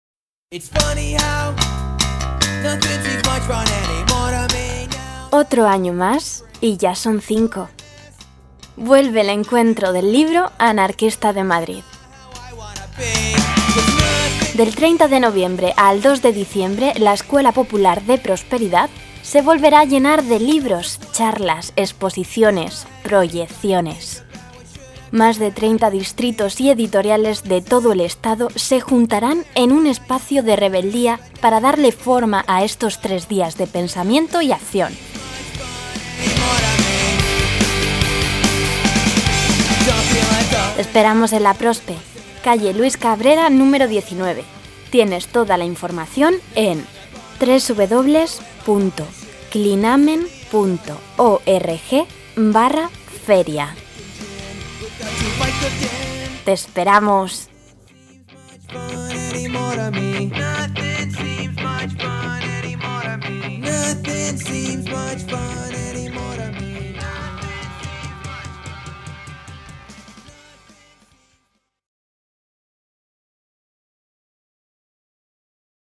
1º Cuña de radio del V Encuentro del libro anarquista 1400Kb]